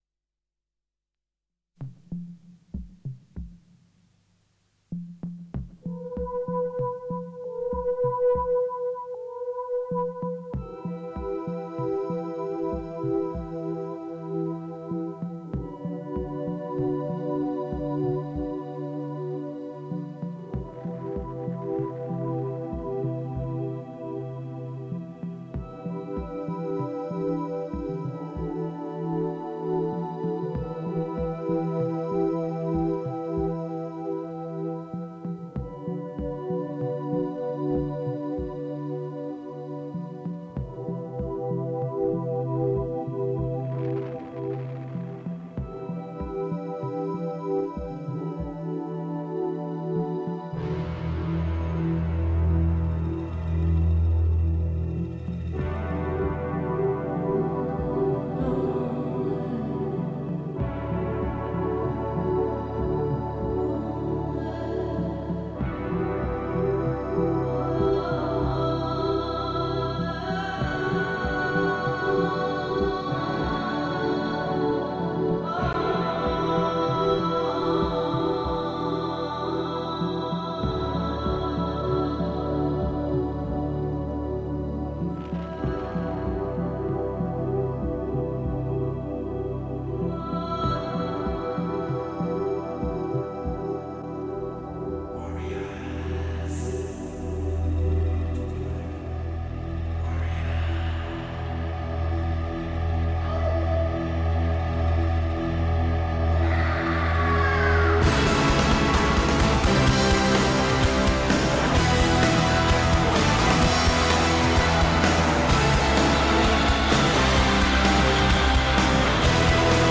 Below is a recording made on the SD1010 and played back by it:
Type: 2-head, single compact cassette deck
Noise Reduction: B